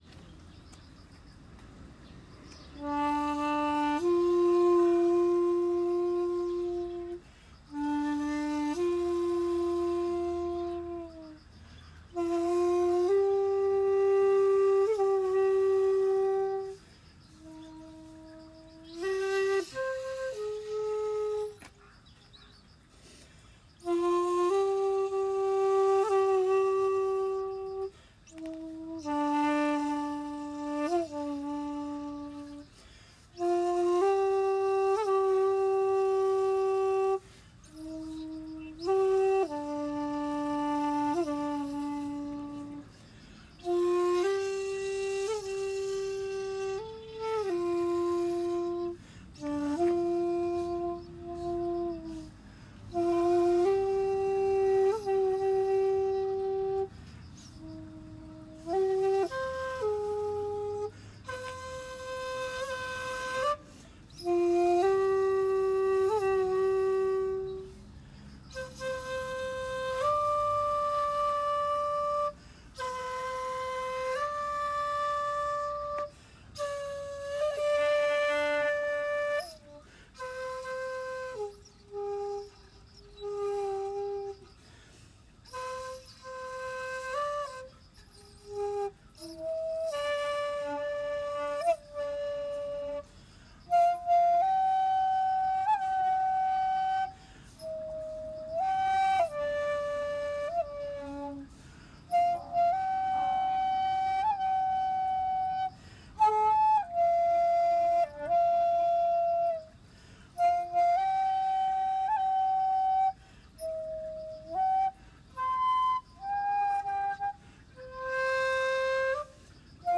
さて今日最初の札所でもあり、本日も多くの寺をまわるので無事に巡れることを願って、尺八の吹奏をしました。
（写真③　五重塔の前で尺八吹奏）
（音源：本山寺での尺八「三谷」一部）
498-本山寺 三谷.mp3